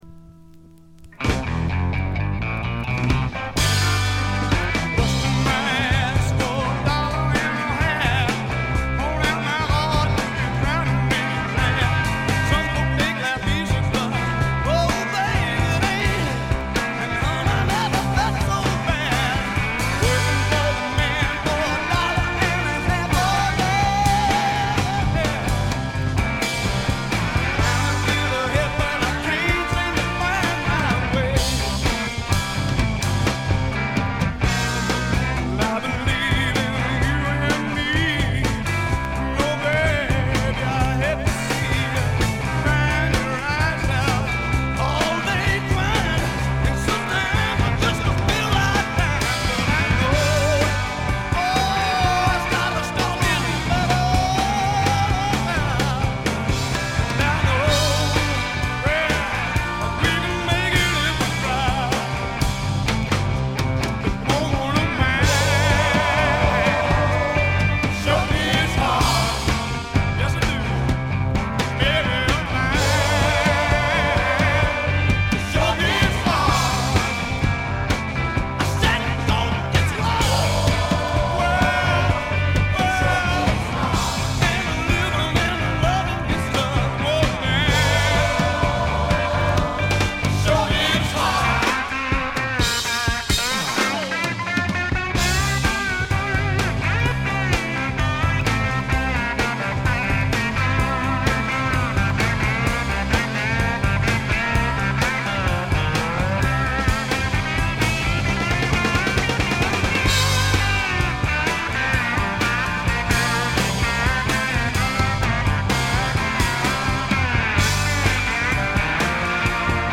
濃厚なスワンプ味に脳天直撃される傑作です。
試聴曲は現品からの取り込み音源です。